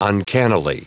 Prononciation du mot uncannily en anglais (fichier audio)
Prononciation du mot : uncannily